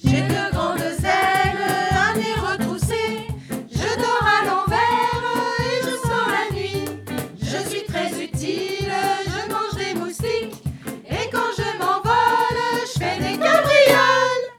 Des comptines pour les petits :
7 assistantes maternelles du RPE des Côteaux et du Plateau ont  collaboré pour mettre en musique cinq comptines qui ont été mises en musique avec des enseignants de l'école Intercommunale de musique et danse de Arche Agglo, et que vous pouvez retrouver ici.